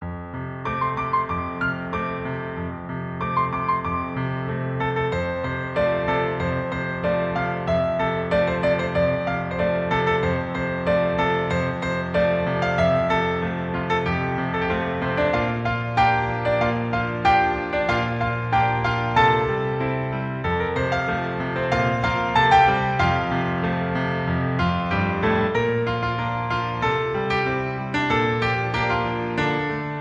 • 🎹 Instrument: Piano Solo
• 🎼 Key: F Major
• 🎶 Genre: TV/Film, OST, Soundtrack